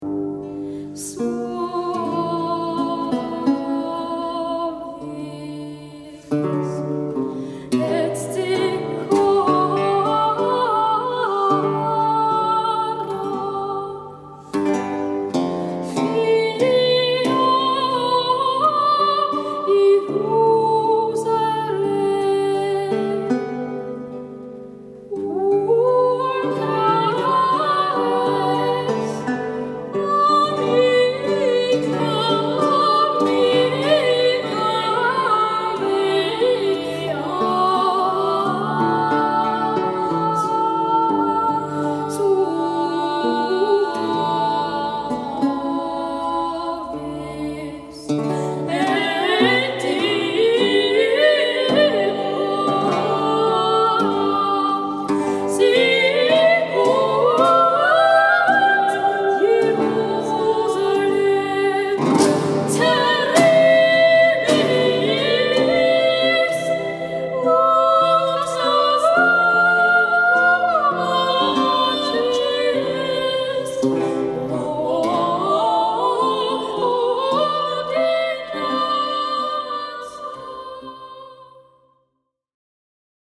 complemented with this beautiful motet from Monteverdi’s Vespers for the Blessed Virgin, composed about 150 years later.